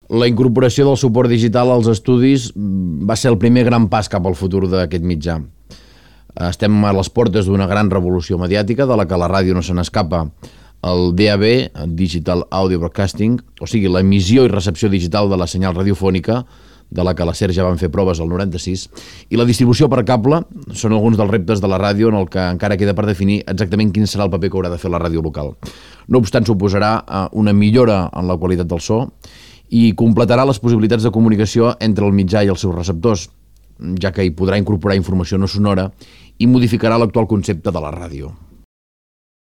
Divulgació